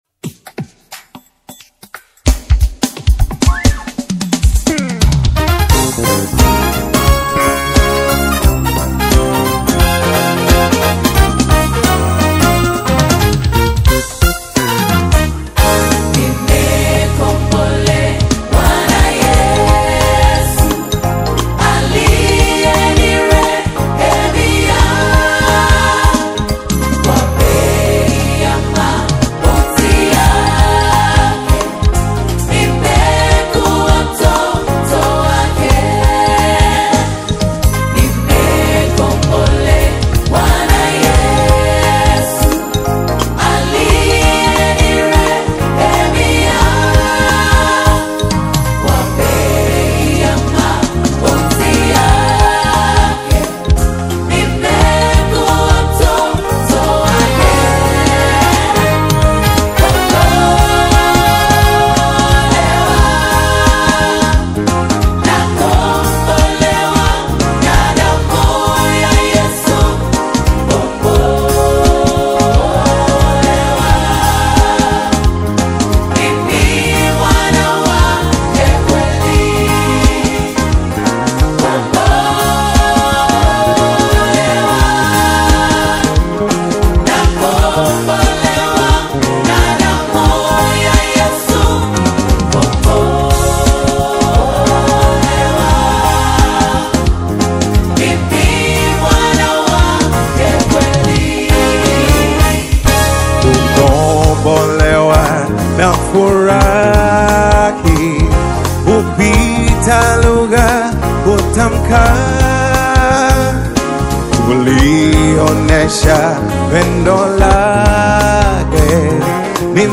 a high-energy, spirit-filled collaboration
the gifted worship leader
Speaking about the live recording